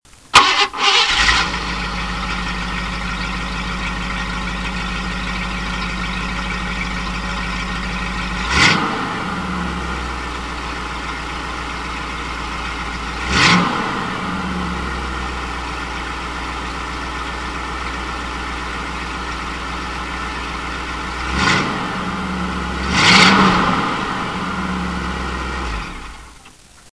Exhaust
2 1/4" pipes and summit performance mufflers and no cats. It's louder than I expected, hopefully it won't wake the neighbors when I go to work at 5:30 am!
exhaust.mp3